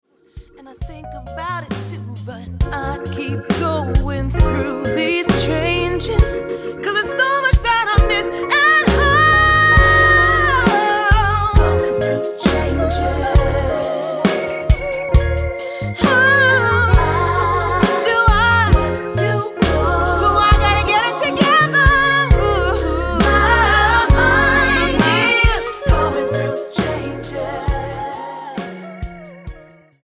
NOTE: Background Tracks 1 Thru 9